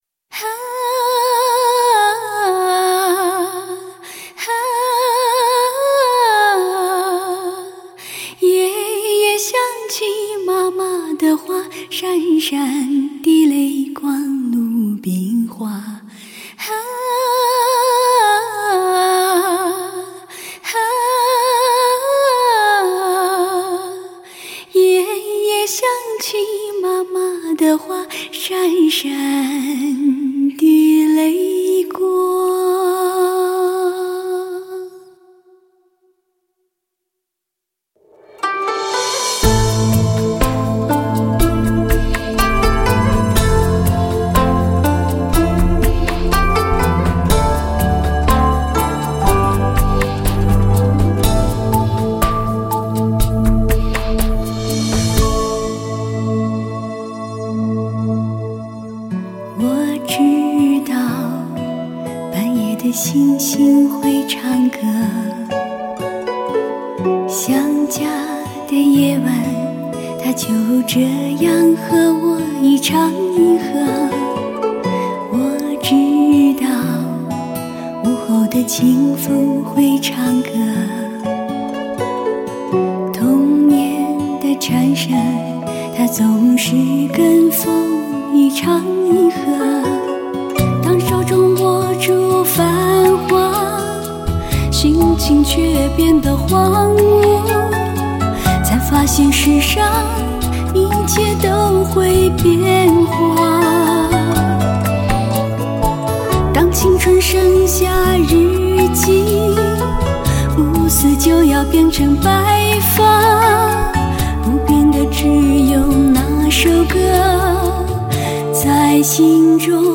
采用1:1直刻无损高品质音源技术，分析力提高，音色更加平衡，失真少，乃发烧乐友至尊HI-FI的视听享受。